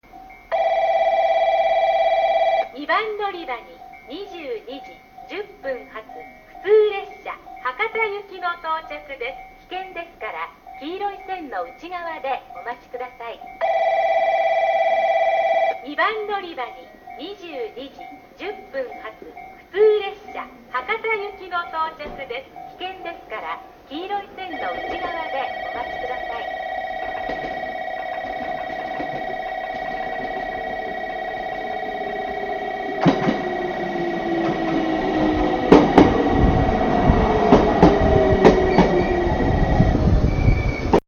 ※当駅の福北ゆたか線ホームは収録環境の都合で音質が悪いです。
2番のりば接近放送（普通　博多行き）